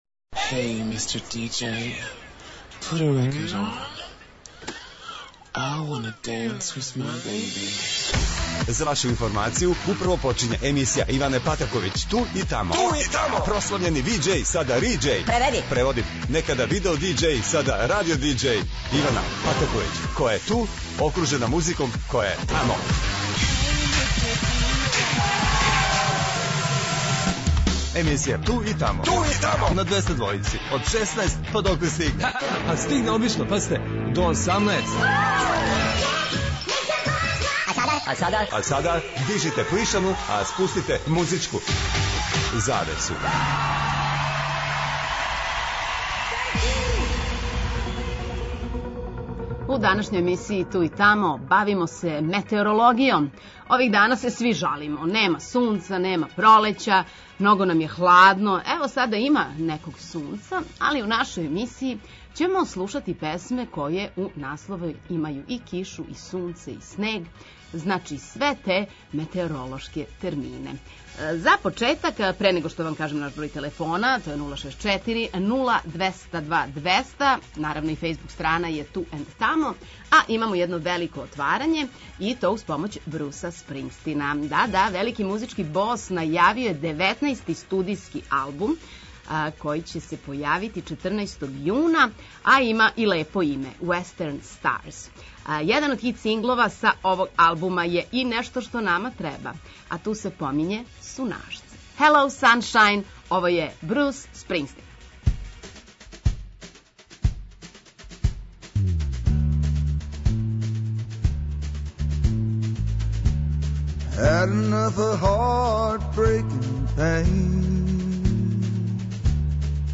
На програму ће бити песме које у насловима имају ветар, кишу, облаке и помало сунца, олује, оркане...